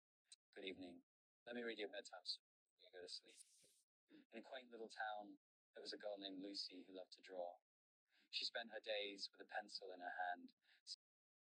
Romantisk bekännelse röstöver
Uttryck dina djupaste känslor med en själfull, uppriktig AI-röst utformad för romantiska bekännelser, kärleksbrev och hjärtfull digital berättande.
Text-till-tal
Uppriktiga röster